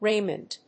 音節Ray・mond 発音記号・読み方
/réɪmənd(米国英語), ˈreɪmʌnd(英国英語)/